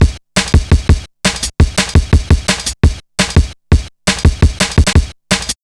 Index of /90_sSampleCDs/Zero-G - Total Drum Bass/Drumloops - 3/track 61 (170bpm)